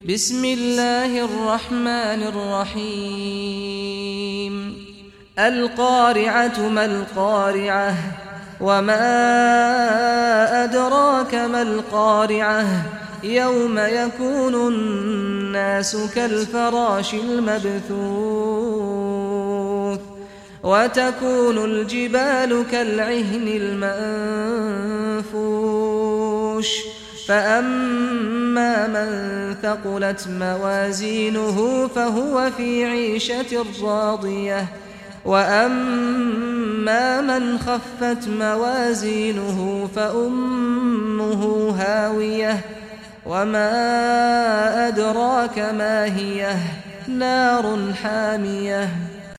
Surah Al Qariah Recitation by Sheikh Saad Ghamdi